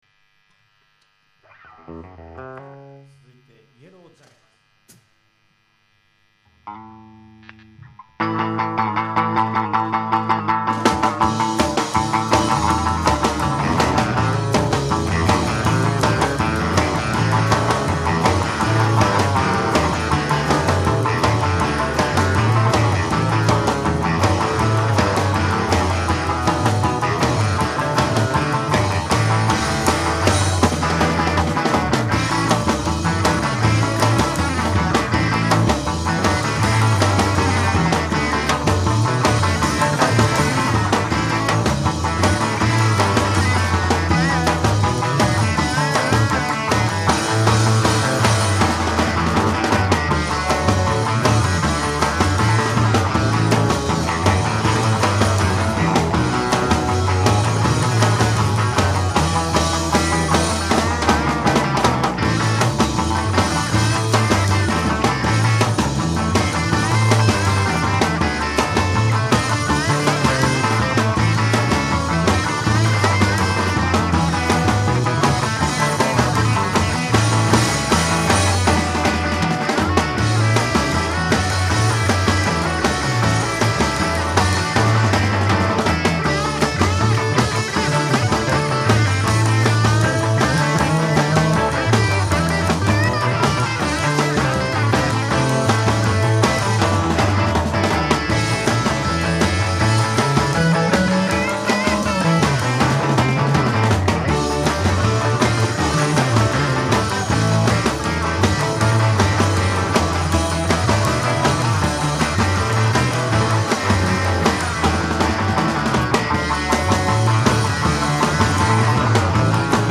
Reproducing the Summer of 1965 Japan concerts.